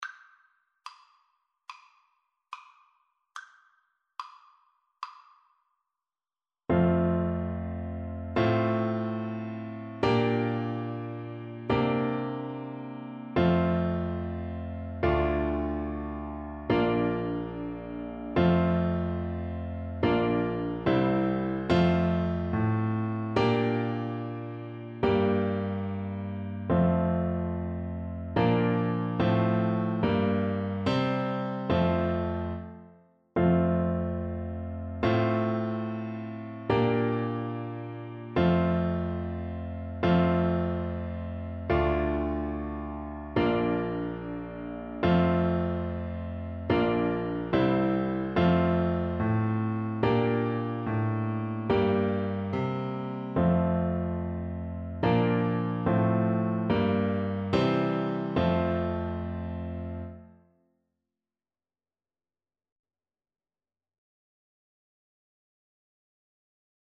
4/4 (View more 4/4 Music)
Moderato
Traditional (View more Traditional Voice Music)